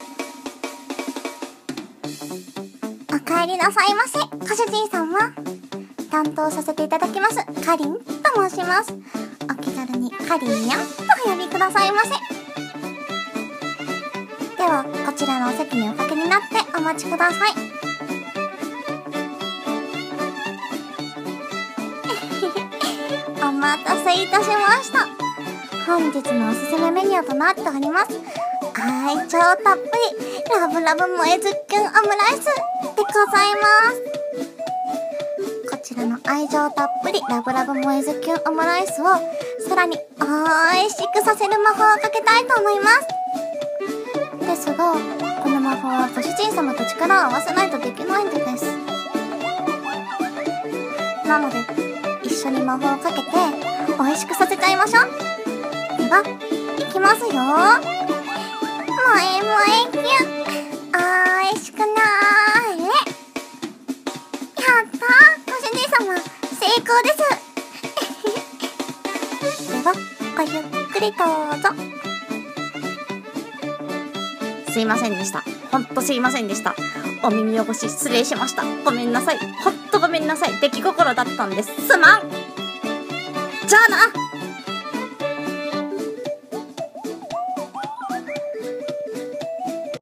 【声劇朗読】メイド喫茶